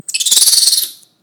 screech2